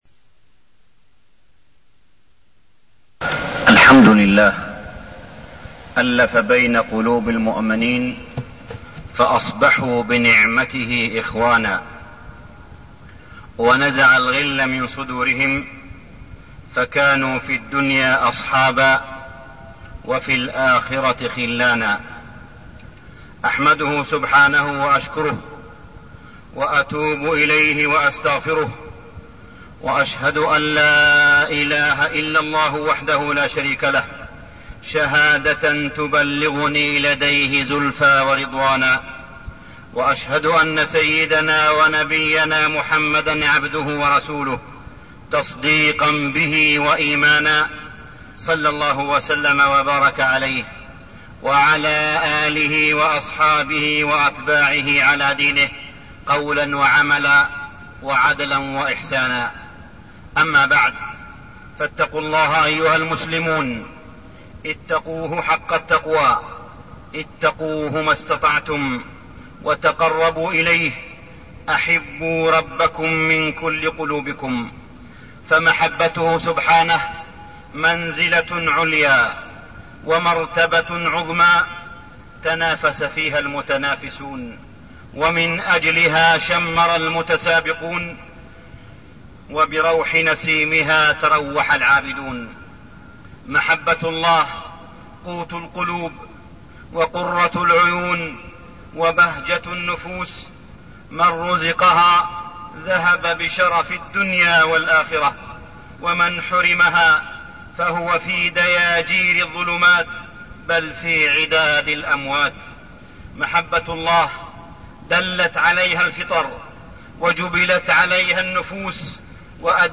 تاريخ النشر ٢٥ شعبان ١٤١٢ هـ المكان: المسجد الحرام الشيخ: معالي الشيخ أ.د. صالح بن عبدالله بن حميد معالي الشيخ أ.د. صالح بن عبدالله بن حميد فضل شهر رمضان